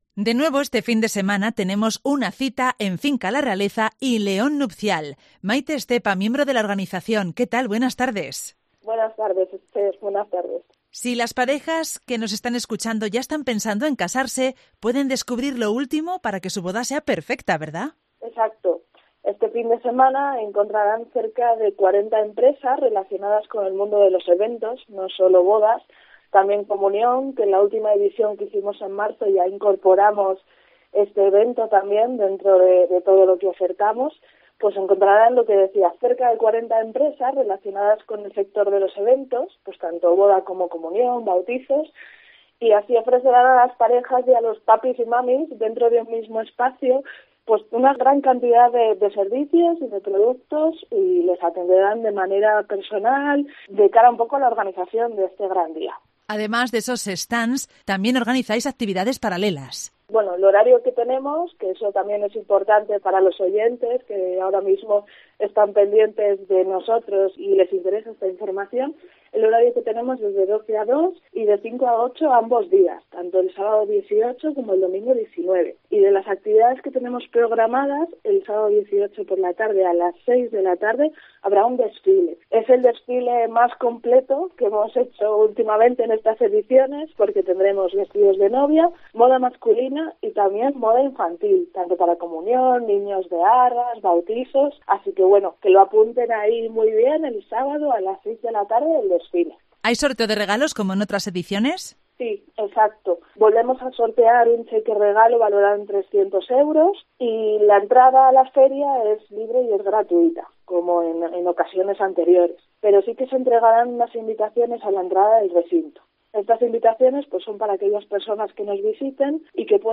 En Directo COPE LEÓN